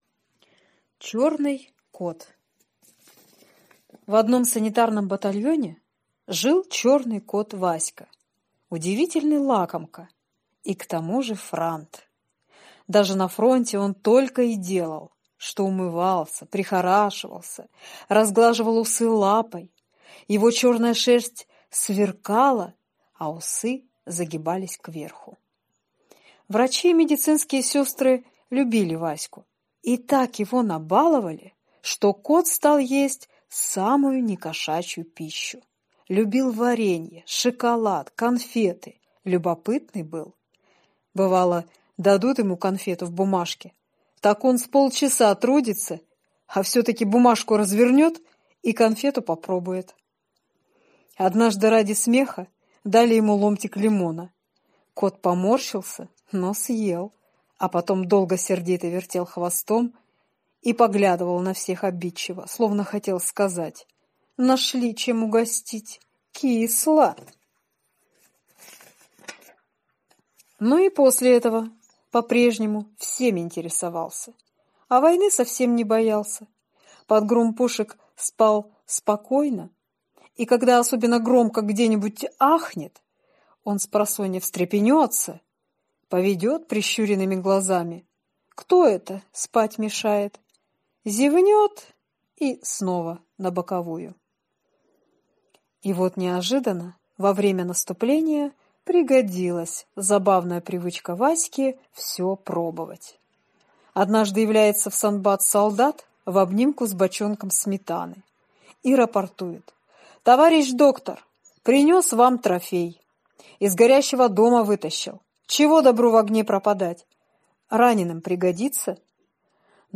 Аудиорассказ «Чёрный кот»